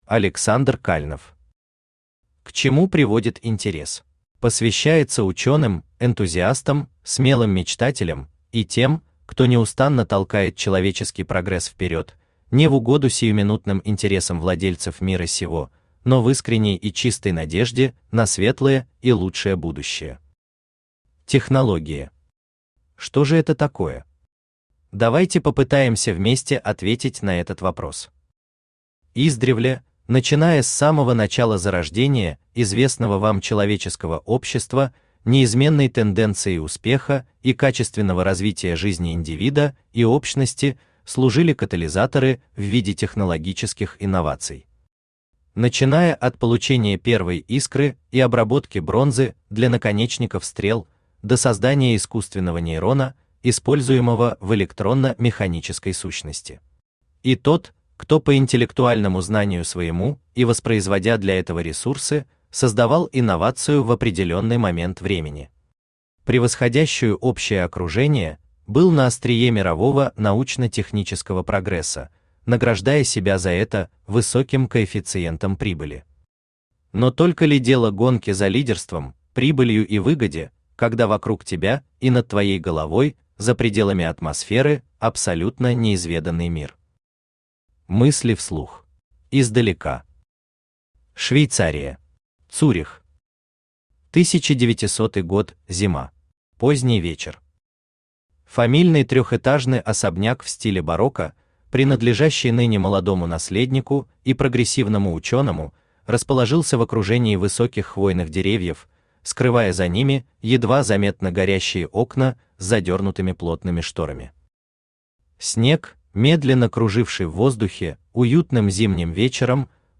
Aудиокнига К чему приводит интерес Автор Александр Александрович Кальнов Читает аудиокнигу Авточтец ЛитРес.